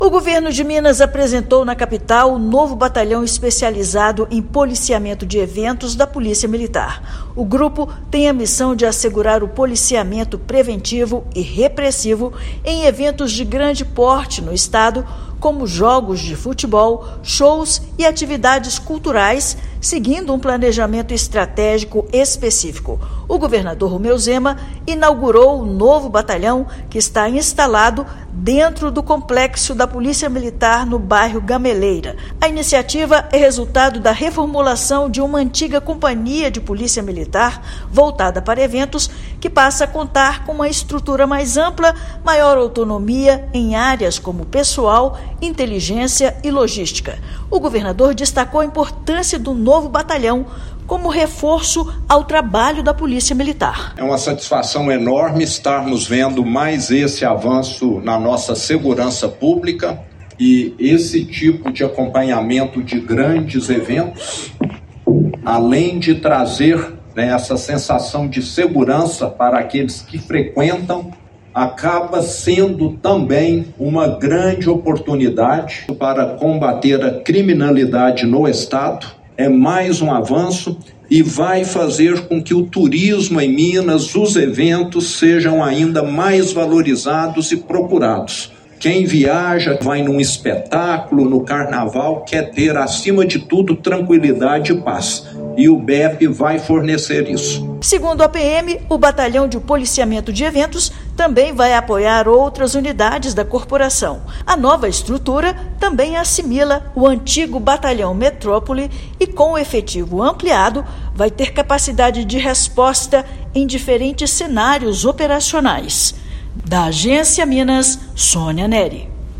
Estado institui o Bepe, da Polícia Militar de Minas Gerais (PMMG), para ampliar estrutura e garantir policiamento preventivo e repressivo em eventos como jogos, shows e atividades culturais. Ouça matéria de rádio.